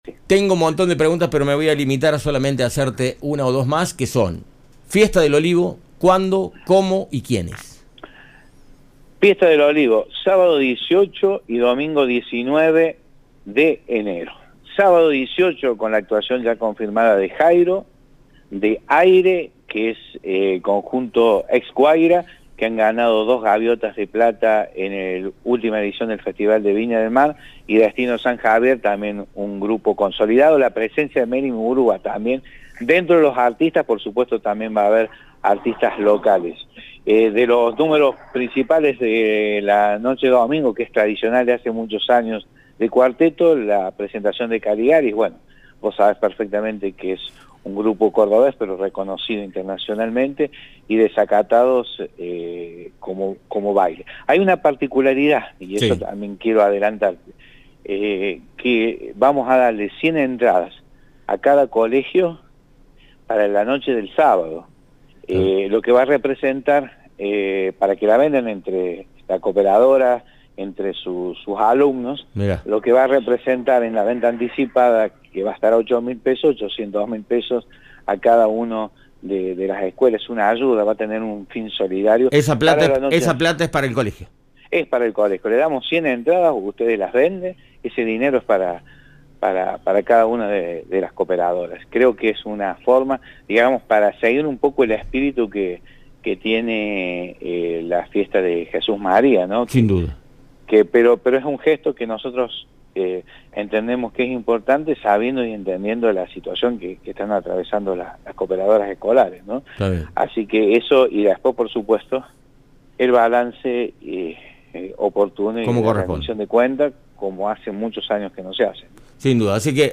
En dialogo con Cadena Centro FM, Renato Raschetti, intendente de la ciudad de Cruz Del Eje, confirmó la grilla para la próxima edición de la Fiesta Nacional Del Olivo.